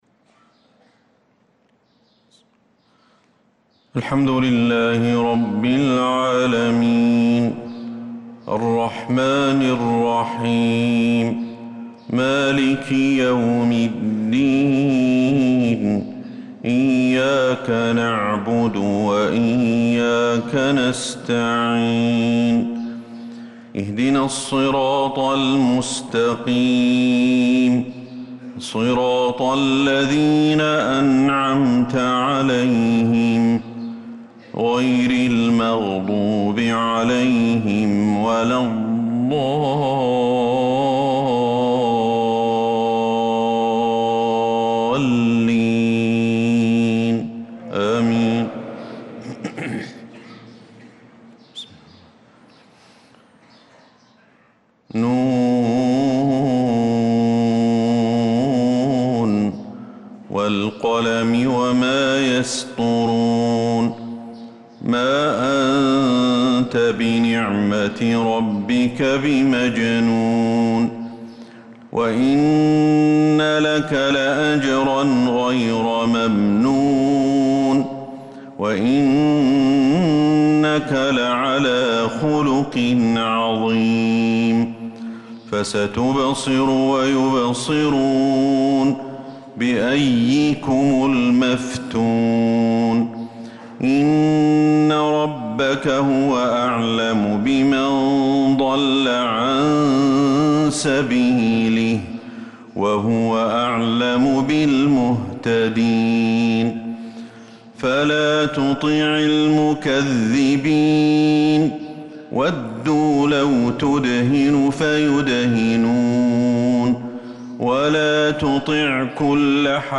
صلاة الفجر